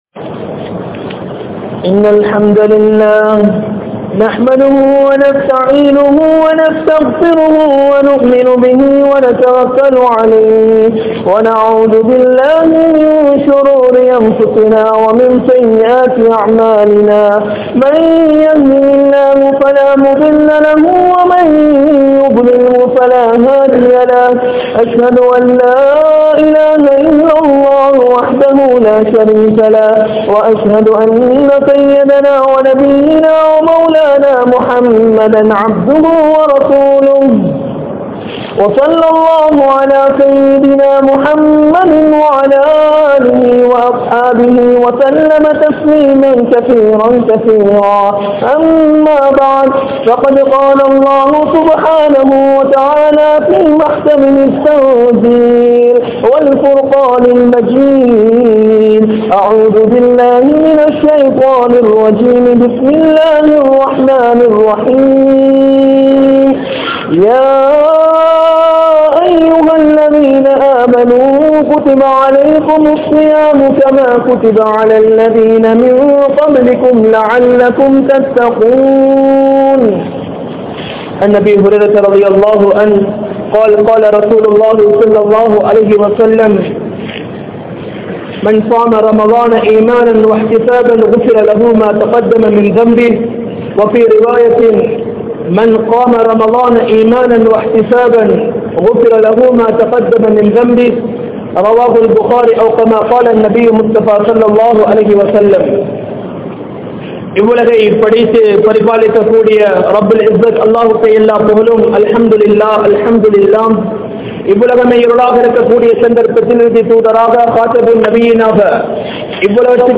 Ramalaanil Sirantha Amal (ரமழானில் சிறந்த அமல்) | Audio Bayans | All Ceylon Muslim Youth Community | Addalaichenai
Majma Ul Khairah Jumua Masjith (Nimal Road)